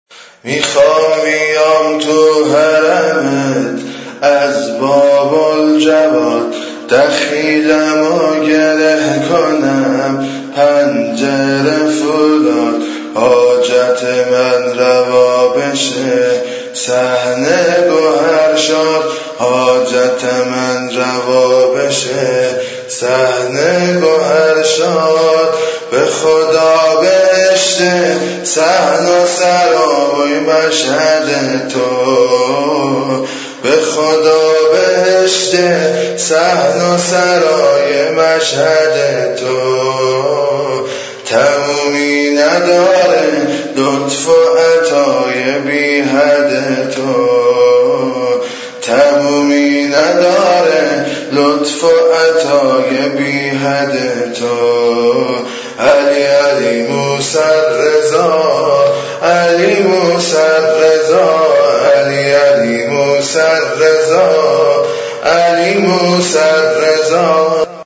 monajat.mp3